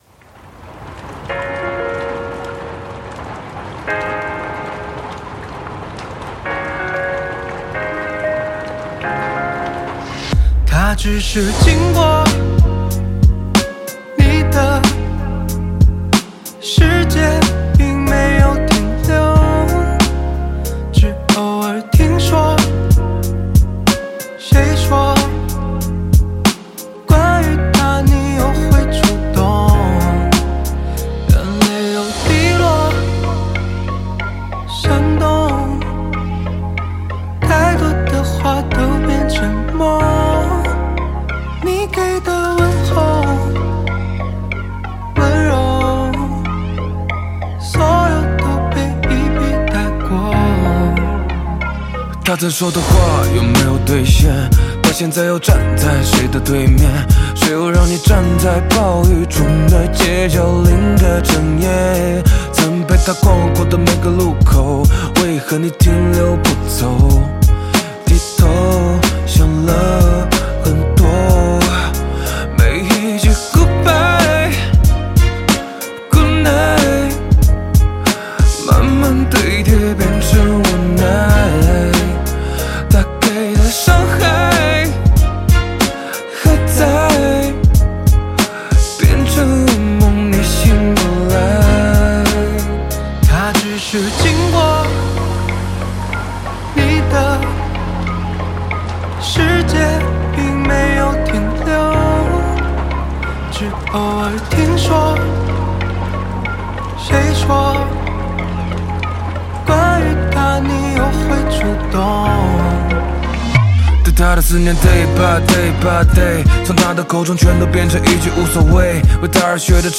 Ps：在线试听为压缩音质节选，体验无损音质请下载完整版